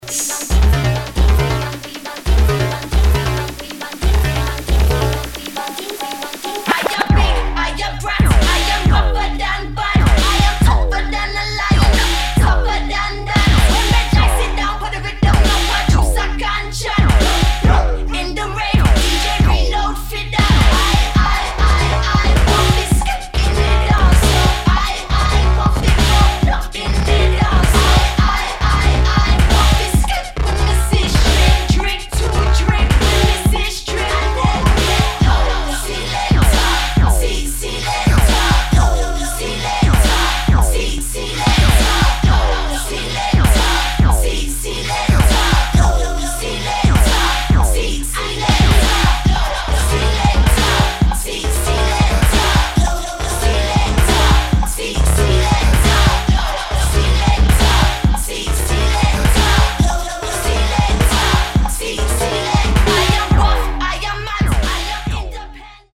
[ UK GARAGE / GRIME ]